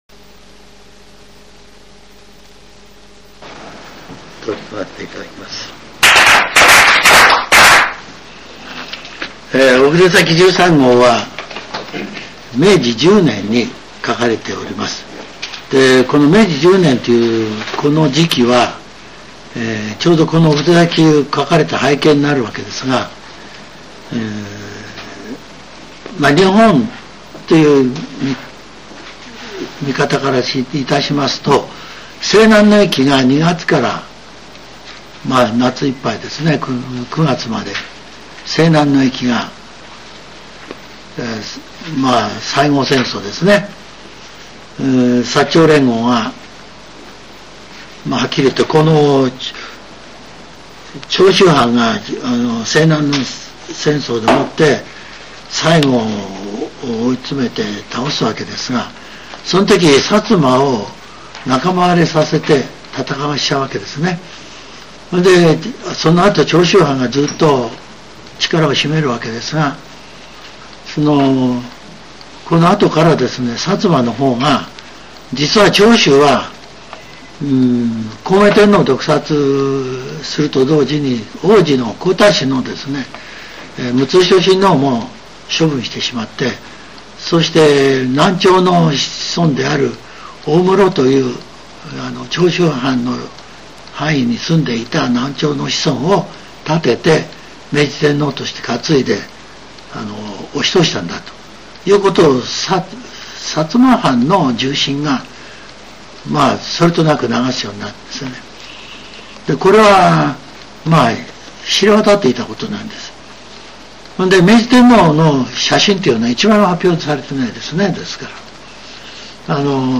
全70曲中69曲目 ジャンル: Speech